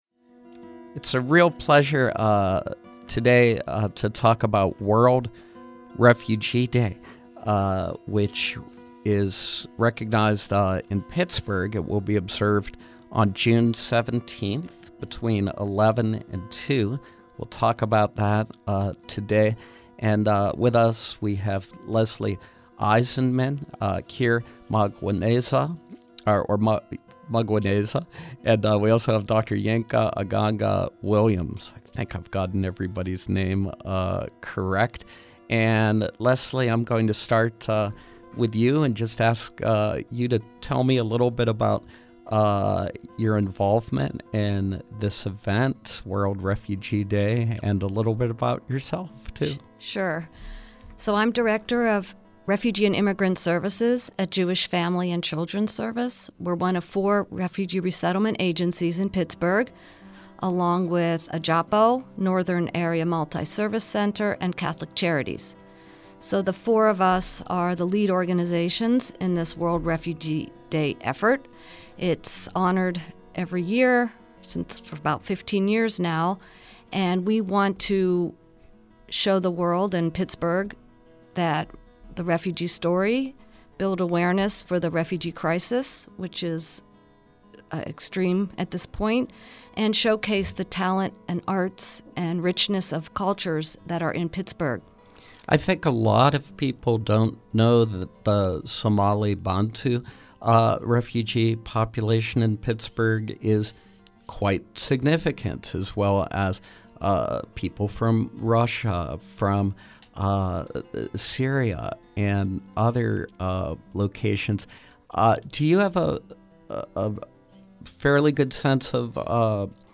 Interview: Pittsburgh Celebration of World Refugee Day